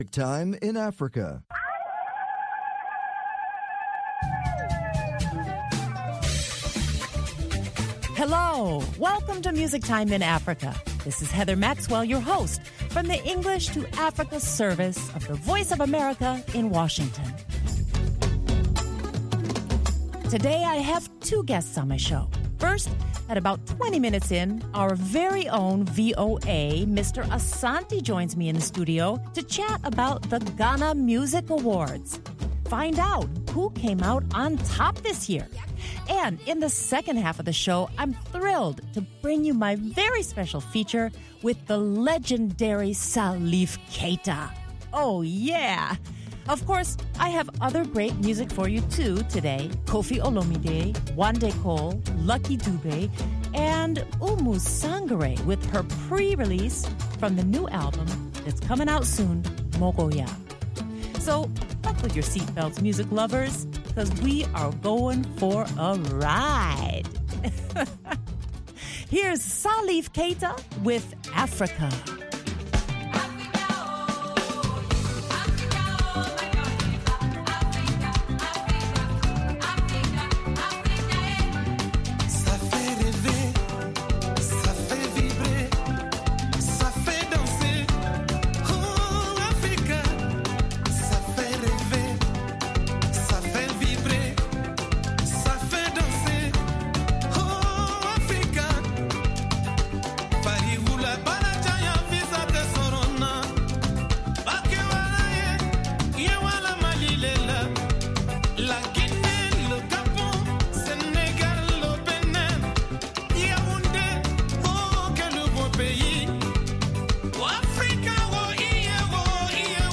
exclusive interviews